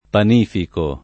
panifico [ pan & fiko ], ‑chi